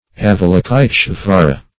Avalokiteshvara synonyms, pronunciation, spelling and more from Free Dictionary.